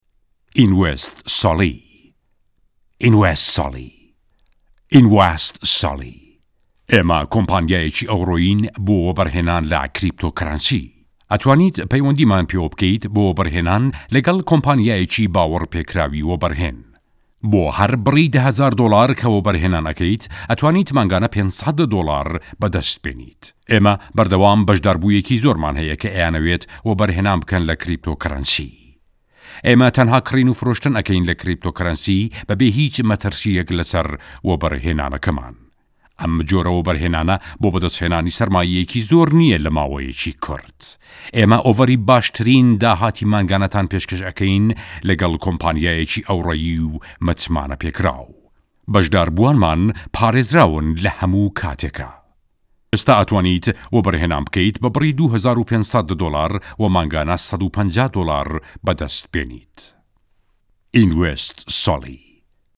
Male
Adult
Commercial